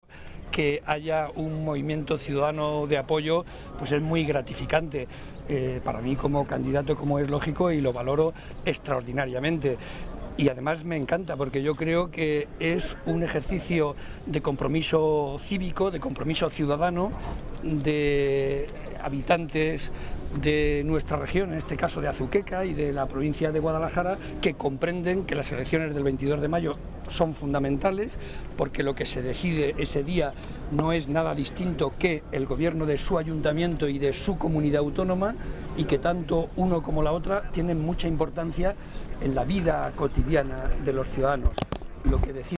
El secretario regional del PSOE y presidente de C-LM, José María Barreda, asistió a la presentación de la plataforma “Vecino a Vecino”
Cortes de audio de la rueda de prensa